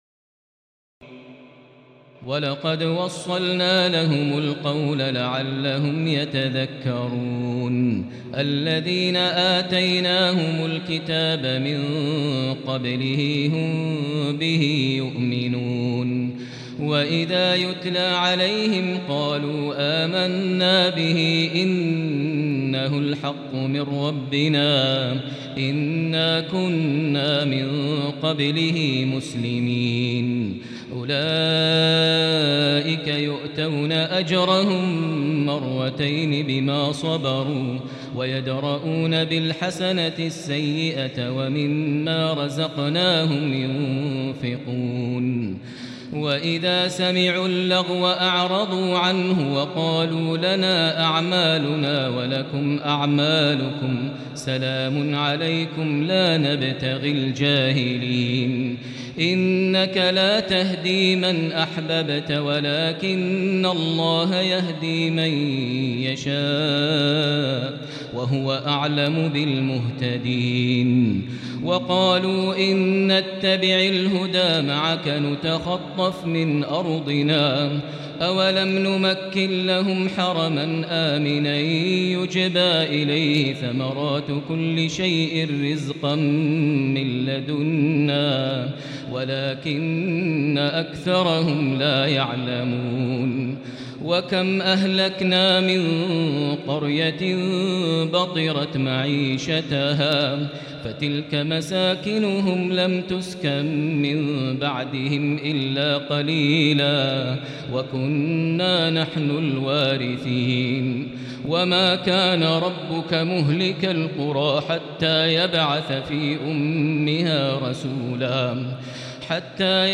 تراويح الليلة التاسعة عشر رمضان 1438هـ من سورتي القصص (51-88) والعنكبوت (1-45) Taraweeh 19 st night Ramadan 1438H from Surah Al-Qasas and Al-Ankaboot > تراويح الحرم المكي عام 1438 🕋 > التراويح - تلاوات الحرمين